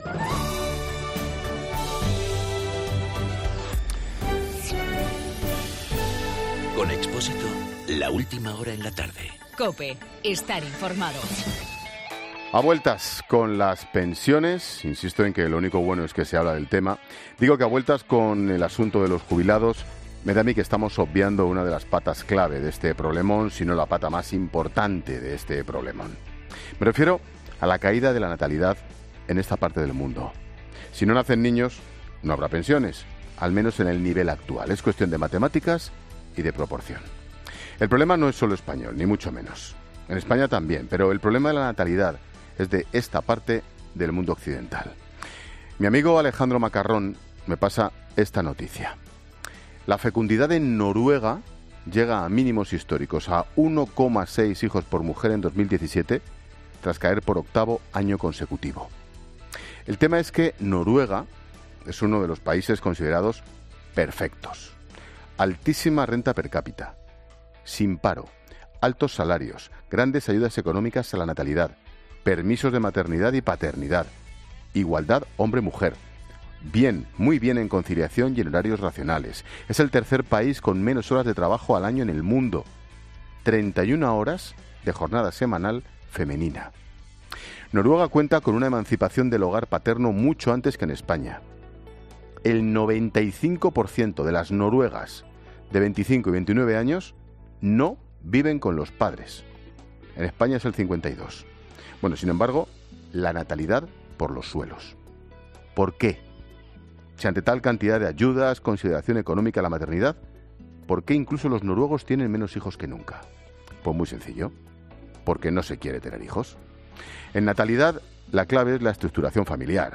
AUDIO: El comentario de Ángel Expósito.